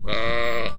Balido de una oveja